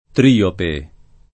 [ tr & ope ]